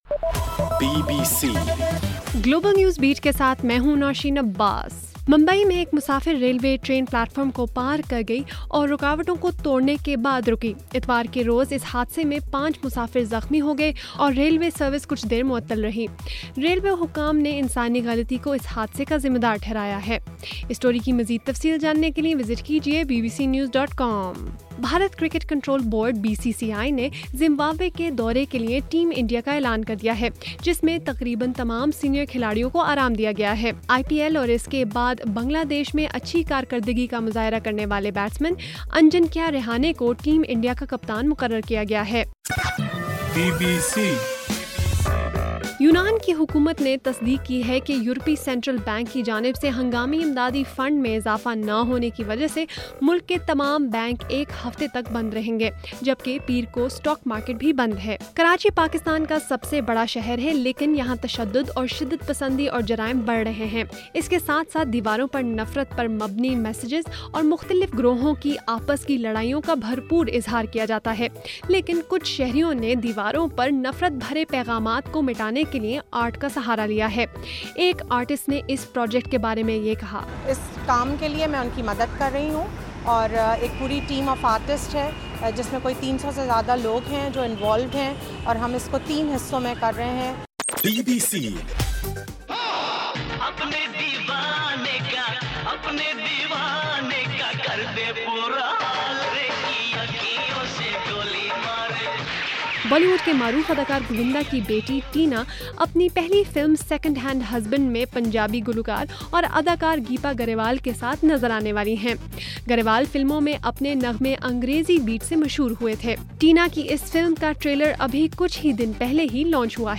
جون 29: رات 10 بجے کا گلوبل نیوز بیٹ بُلیٹن